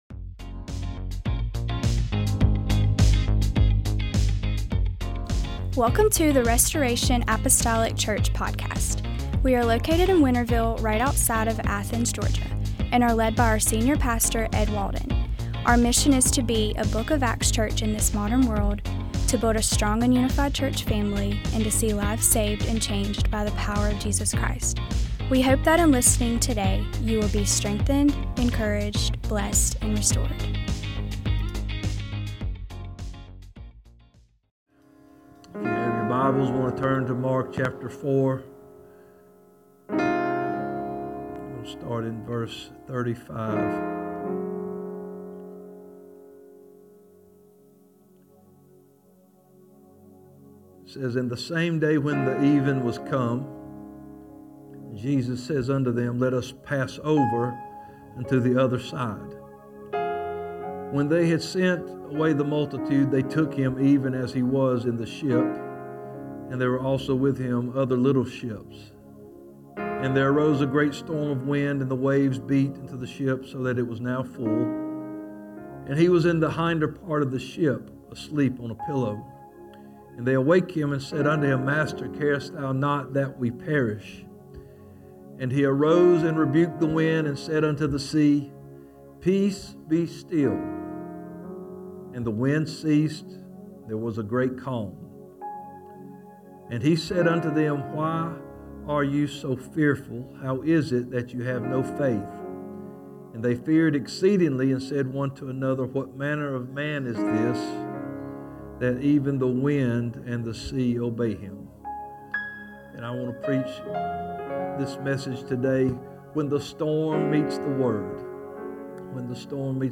Restoration Apostolic Church When the Storm Meets the Word Mar 15 2026 | 00:36:18 1x 00:00 / 00:36:18 Subscribe Share Apple Podcasts Spotify Overcast RSS Feed Share Link Embed